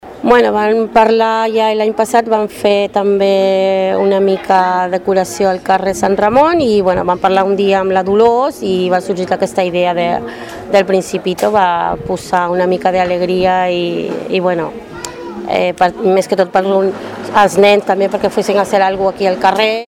una de les organitzadores.